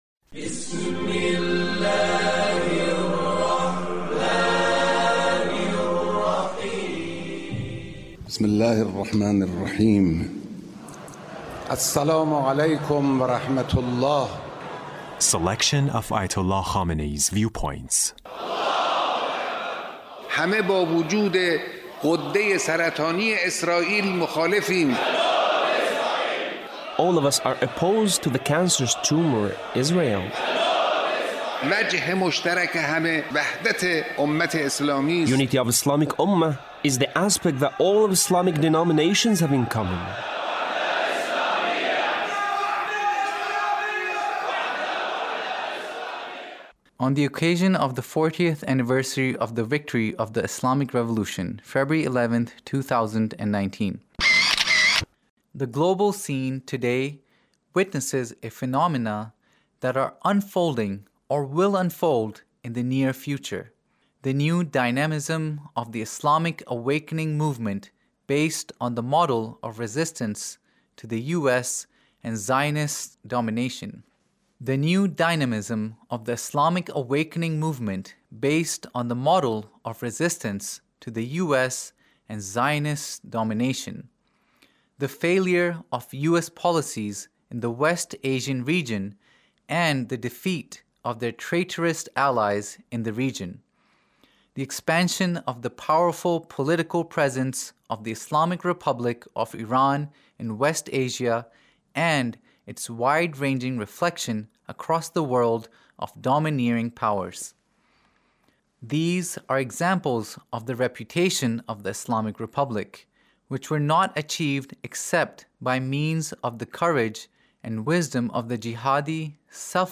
Leader's Speech (1899)